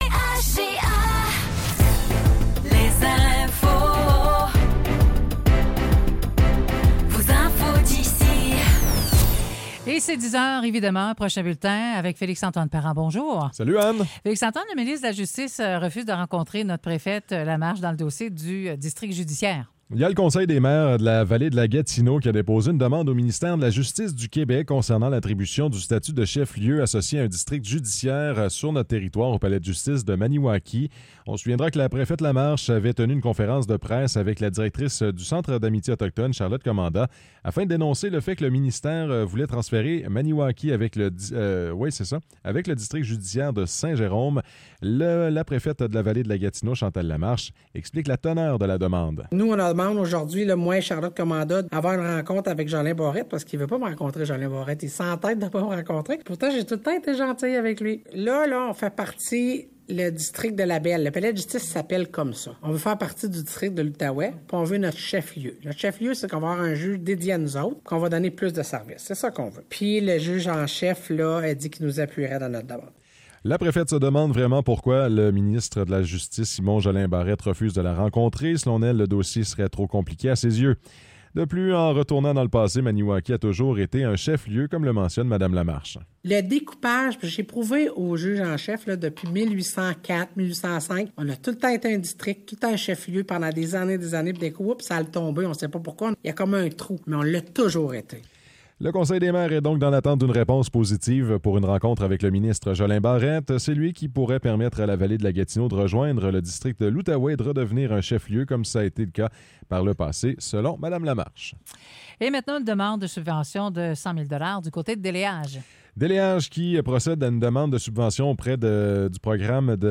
Nouvelles locales - 11 juin 2024 - 10 h